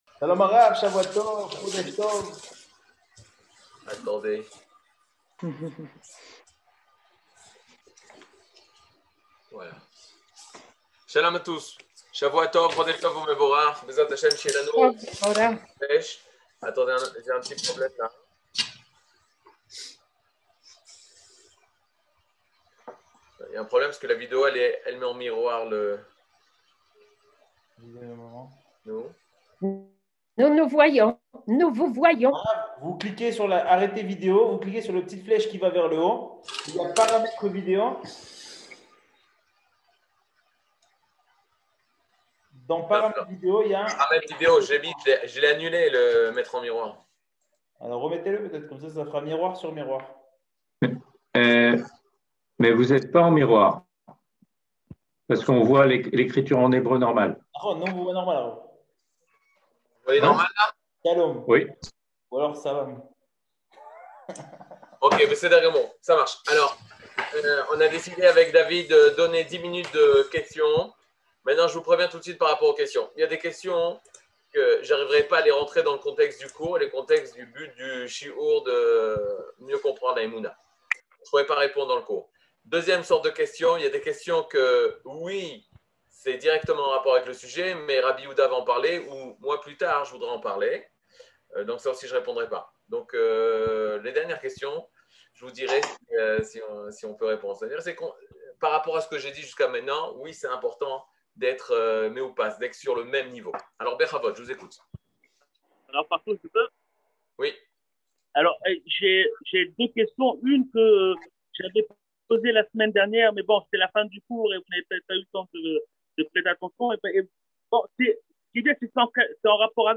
Catégorie Le livre du Kuzari partie 16 01:00:38 Le livre du Kuzari partie 16 cours du 16 mai 2022 01H 00MIN Télécharger AUDIO MP3 (55.5 Mo) Télécharger VIDEO MP4 (138.51 Mo) TAGS : Mini-cours Voir aussi ?